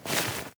glider_open.ogg